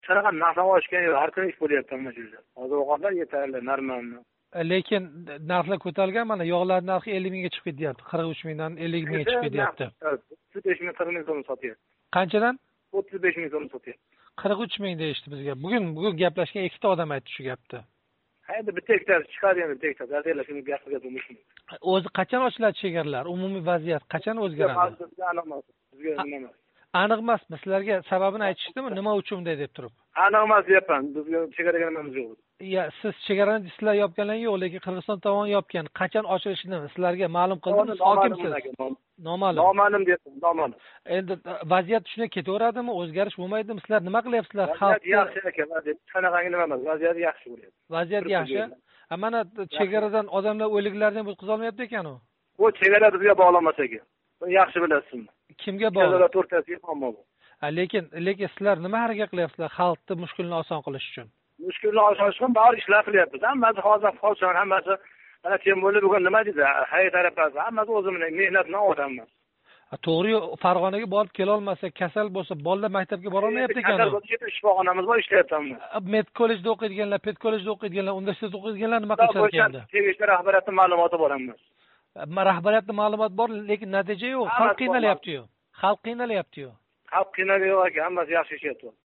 Сўх ҳокими Таваккал Раҳимов билан суҳбат